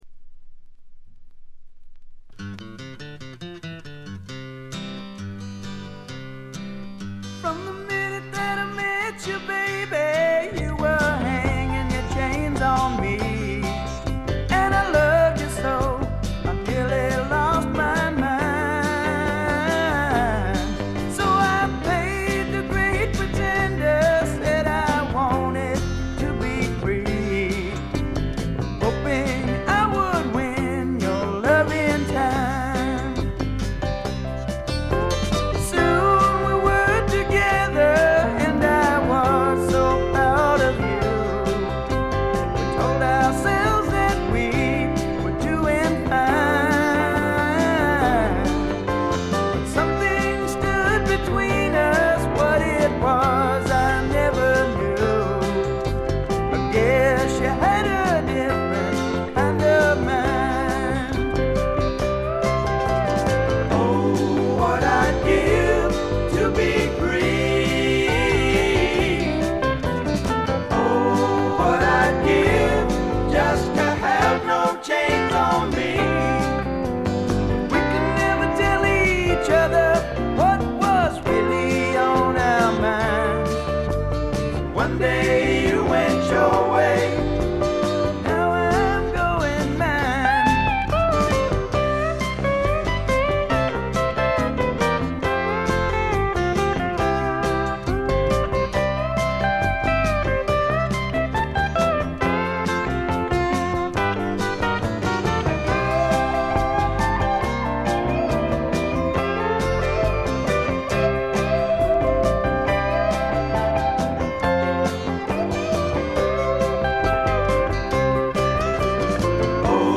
軽いチリプチが少々。
試聴曲は現品からの取り込み音源です。